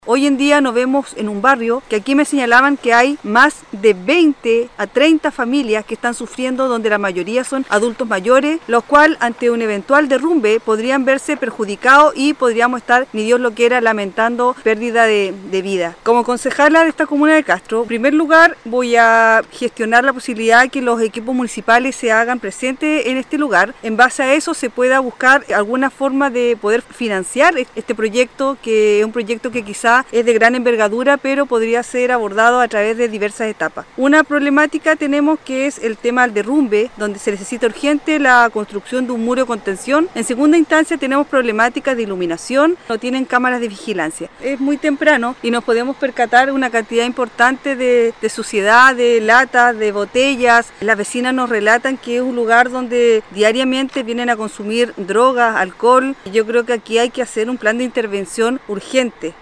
Al respecto, fue la concejala de Castro Yoanna Morales quien también se refirió a lo peligroso de la situación: